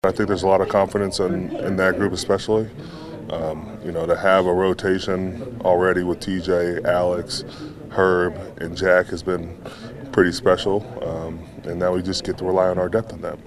Defensive lineman Cam Heyward says, even without Watt, Alex Highsmith, Nick Herbig, and rookie Jack Sawyer can put plenty of pressure on Dolphins quarterback Tua Tagovailoa.